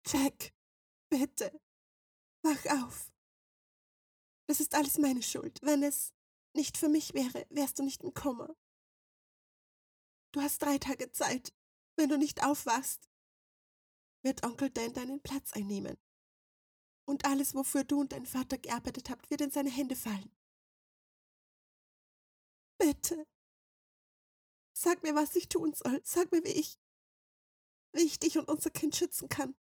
Female
Österreichisches Deutsch, warmherzig, freundlich, charmant, beruhigend, energisch
Movie Trailers
A Short Demo Of A Film I Narrated